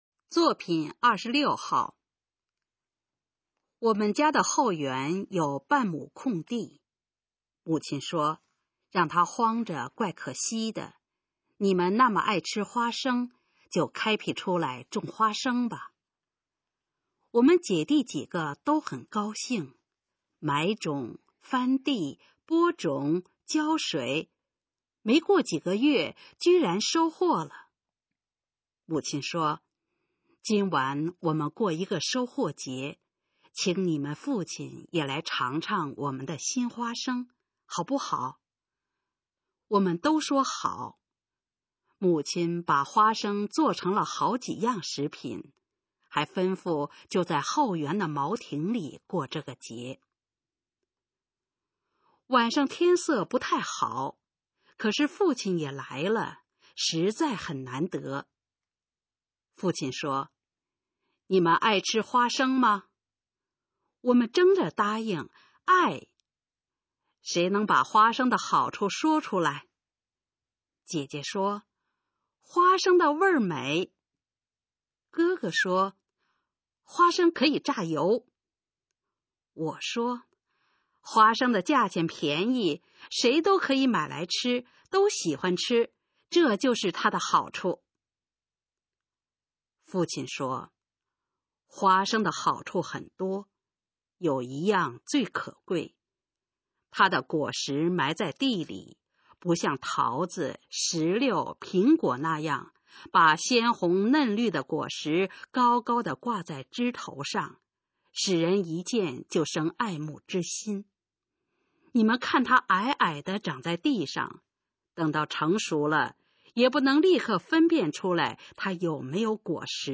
《落花生》示范朗读_水平测试（等级考试）用60篇朗读作品范读